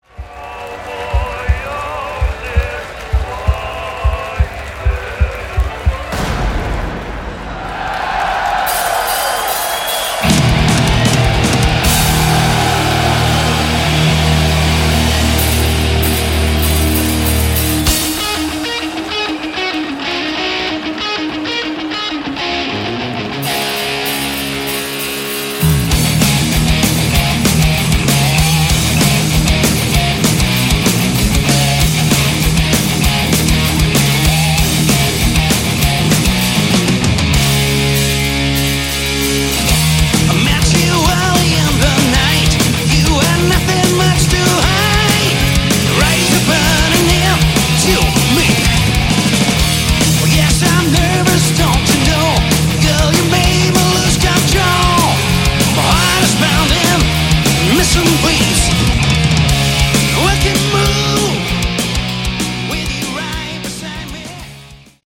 Category: Melodic Hard Rock
vocals, guitar
bass
drums